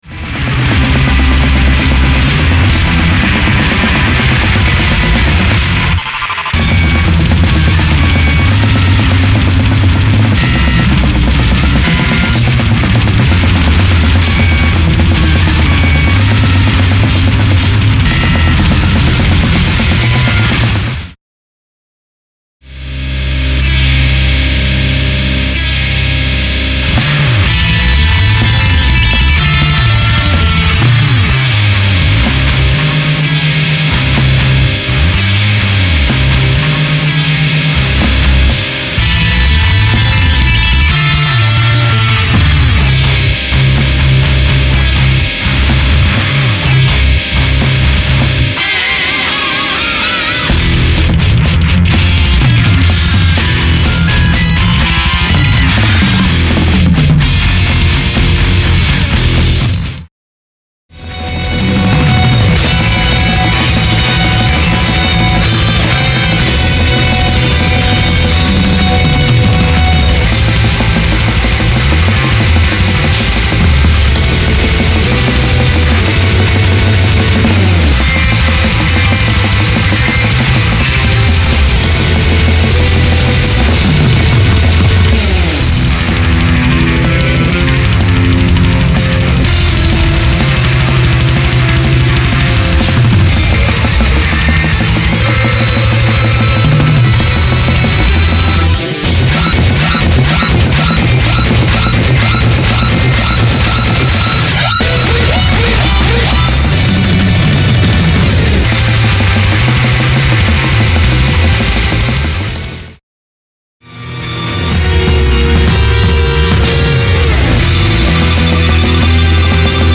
Австрийская блэк-метал-формация